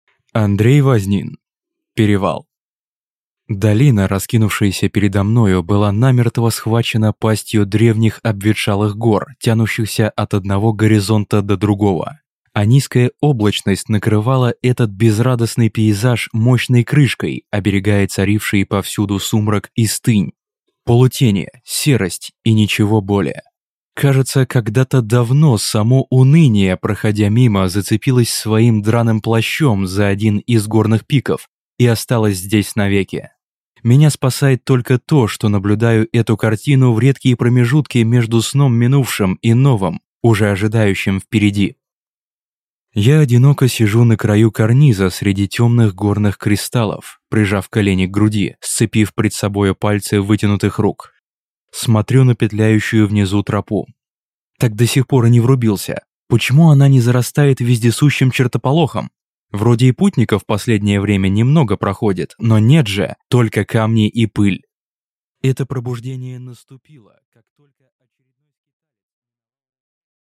Аудиокнига Перевал | Библиотека аудиокниг